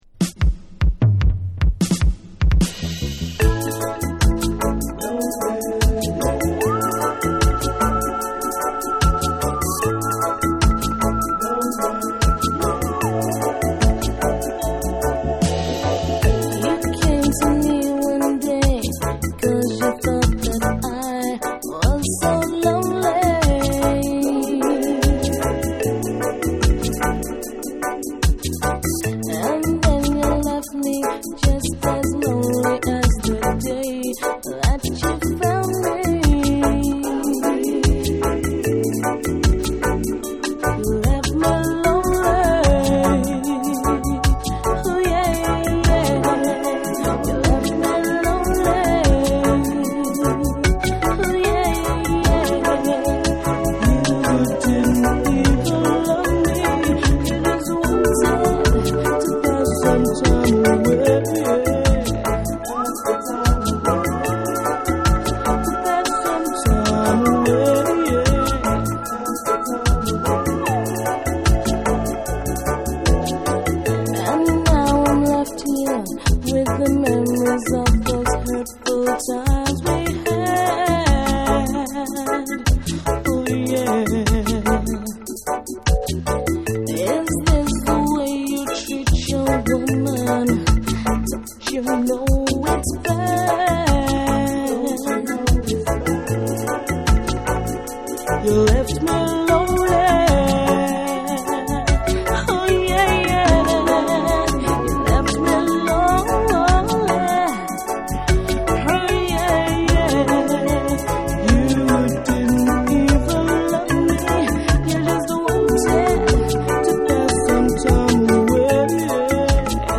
多くのラヴァーズ・ロック作品を残すフィメール・シンガー
REGGAE & DUB / LOVERS